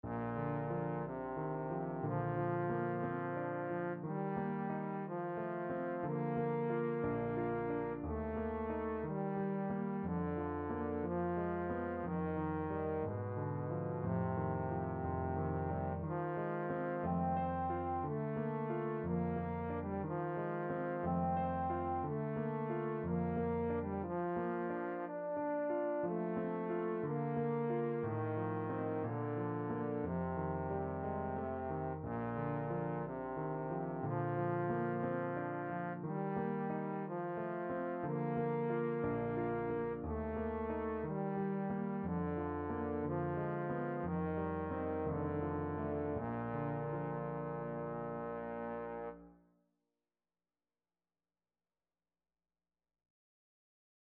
Trombone
Bb major (Sounding Pitch) (View more Bb major Music for Trombone )
~ = 60 Andantino (View more music marked Andantino)
2/4 (View more 2/4 Music)
Bb3-D5
Classical (View more Classical Trombone Music)